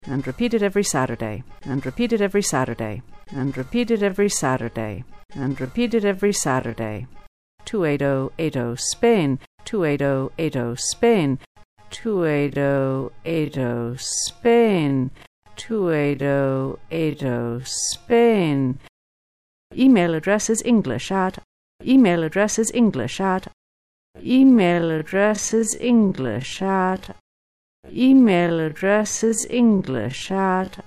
Here you will find two listening lessons based on the same snippets from an English Language Broadcast on the Spanish National Radio station.